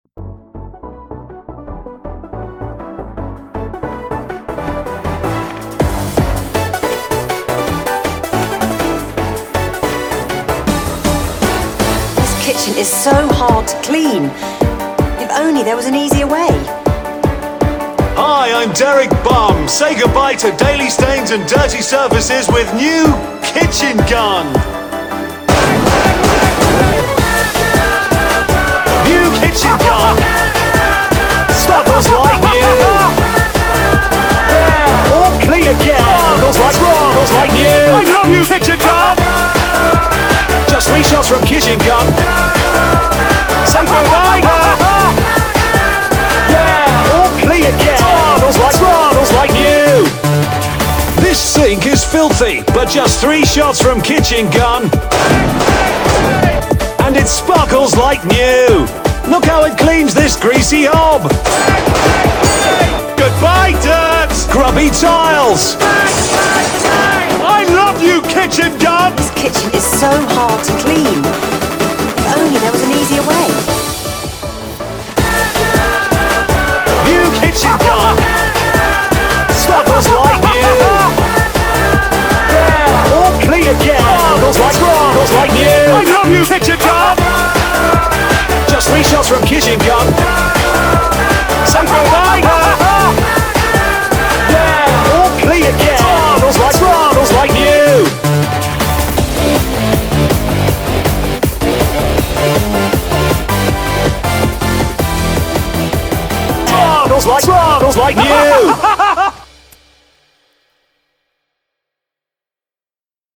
BPM160
Audio QualityPerfect (High Quality)
This epic beat